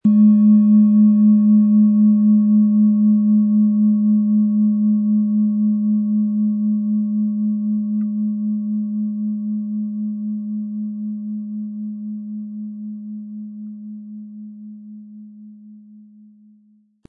Handgetriebene, tibetanische Planetenklangschale Mond.
Wie klingt diese tibetische Klangschale mit dem Planetenton Mond?
Im Sound-Player - Jetzt reinhören können Sie den Original-Ton genau dieser Schale anhören.
Durch die traditionsreiche Herstellung hat die Schale stattdessen diesen einmaligen Ton und das besondere, bewegende Schwingen der traditionellen Handarbeit.
Wohltuende Klänge bekommen Sie aus dieser Schale, wenn Sie sie mit dem kostenlosen Klöppel sanft anspielen.
MaterialBronze